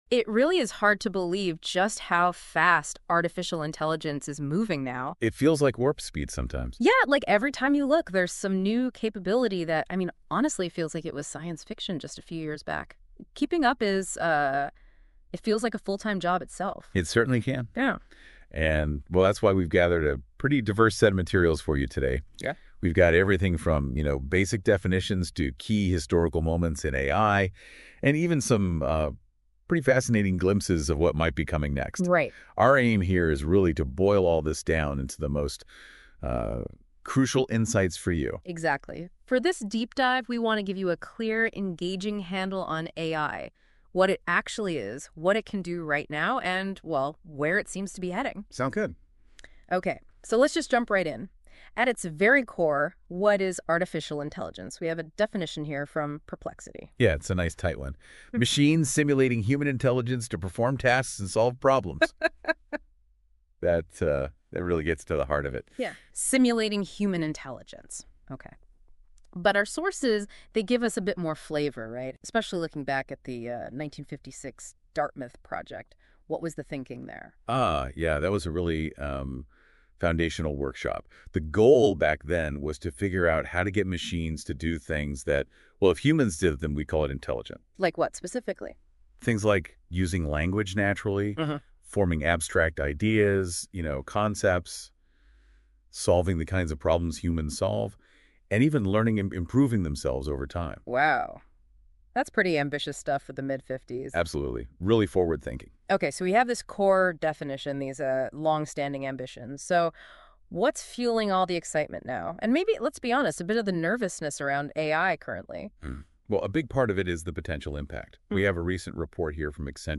Review the presentation by listening to this AI generated podcast courtesy of Google NotebookLM. I gave it a copy of my slides and the artificial speakers will tell you what they understood from them in a conversational style.